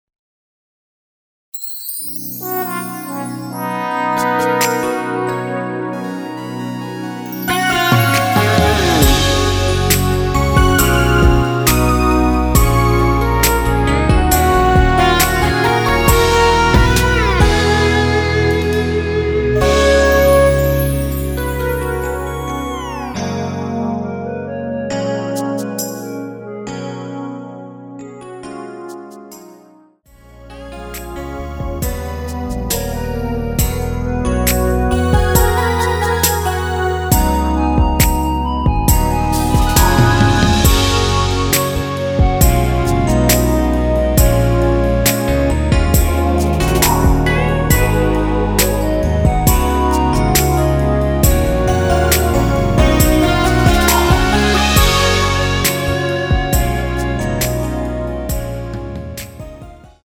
원키에서(-1)내린 (짧은편곡) 멜로디 포함된 MR입니다.
F#
앞부분30초, 뒷부분30초씩 편집해서 올려 드리고 있습니다.
중간에 음이 끈어지고 다시 나오는 이유는